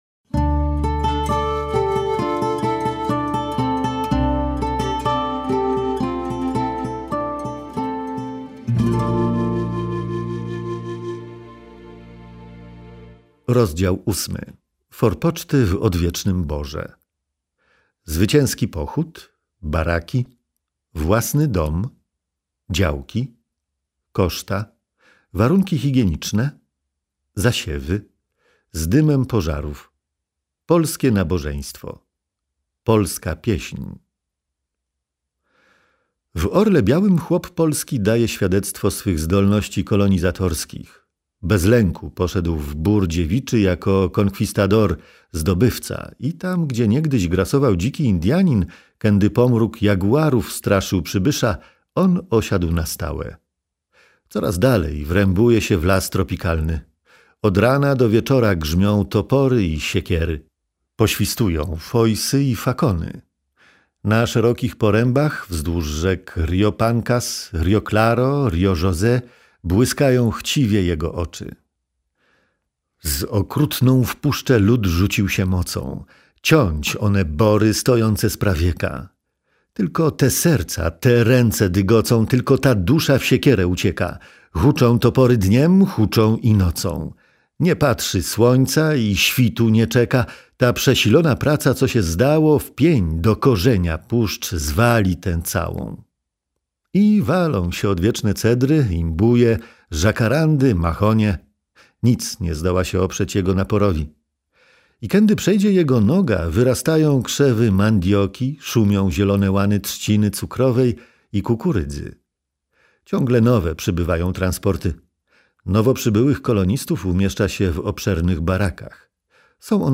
Audiobook DROGĄ PIELGRZYMÓW
Został wydany już audiobook - w formie słuchowiska - książki sługi Bożego o. Ignacego Posadzego „Drogą pielgrzymów".  Książka ta jest relacją z objazdów przez Ojca Ignacego osad polskich w Południowej Ameryce w latach 1929 oraz 1930-1931.